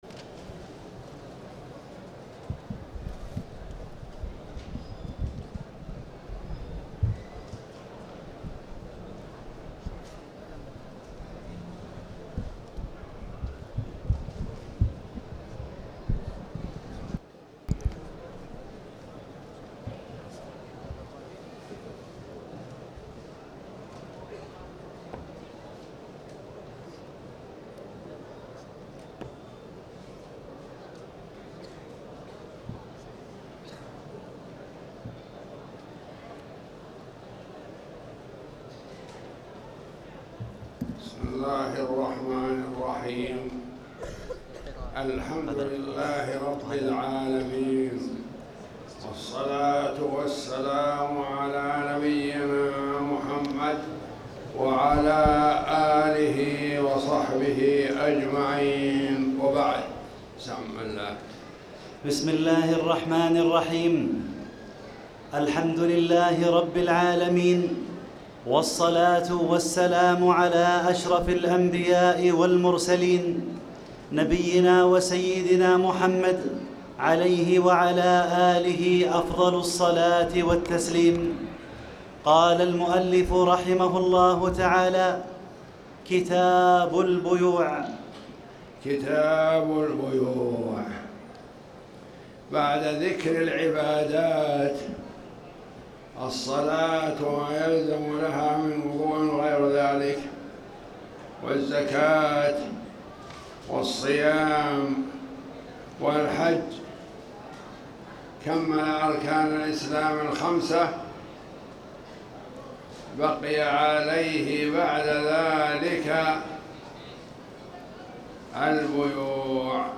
تاريخ النشر ٢٩ ربيع الأول ١٤٣٩ هـ المكان: المسجد الحرام الشيخ